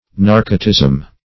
Search Result for " narcotism" : The Collaborative International Dictionary of English v.0.48: Narcotism \Nar"co*tism\ (n[aum]r"k[-o]*t[i^]z'm), n. [Cf. F. narcotisme.]
narcotism.mp3